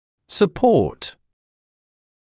[sə’pɔːt][сэ’поːт]поддержка; поддерживать